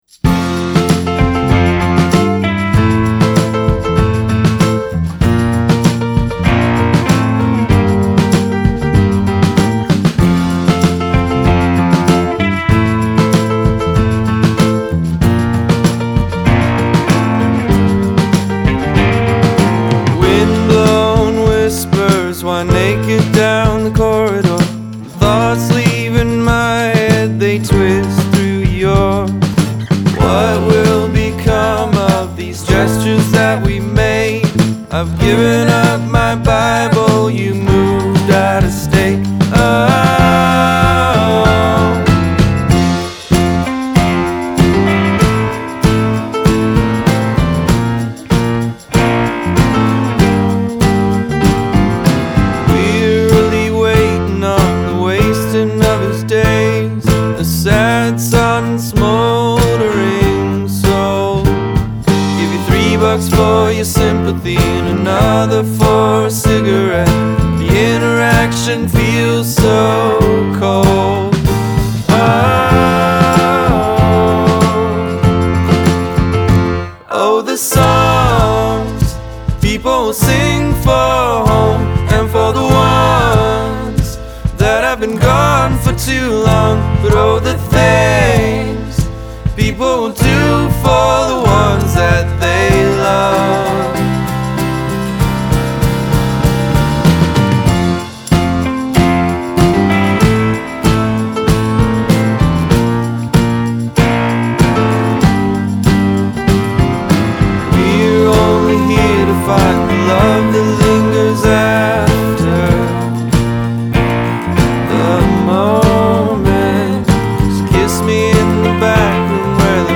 a perfectly crafted pop song